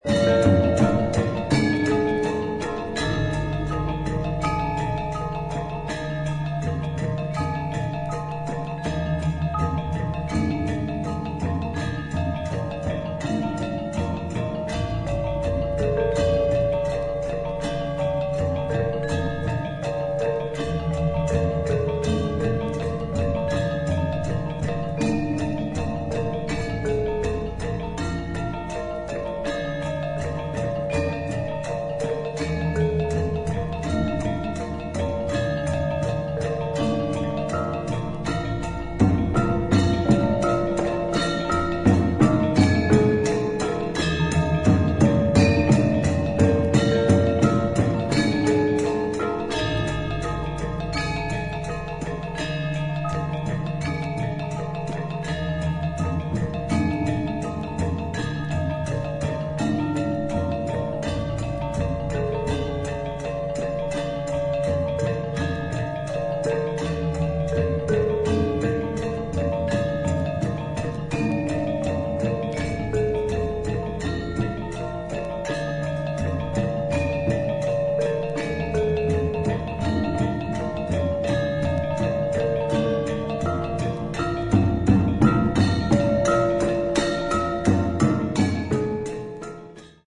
ARTIST : GAMELAN GONG SEKAR ANJAR, GENDER WAJANG QUARTET, DR. MANTLE HOOD